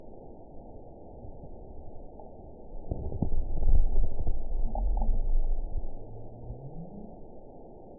event 917040 date 03/09/23 time 23:16:40 GMT (2 years, 7 months ago) score 9.52 location TSS-AB03 detected by nrw target species NRW annotations +NRW Spectrogram: Frequency (kHz) vs. Time (s) audio not available .wav